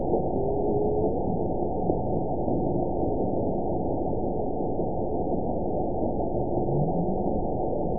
event 922530 date 01/27/25 time 11:22:19 GMT (10 months, 1 week ago) score 9.51 location TSS-AB02 detected by nrw target species NRW annotations +NRW Spectrogram: Frequency (kHz) vs. Time (s) audio not available .wav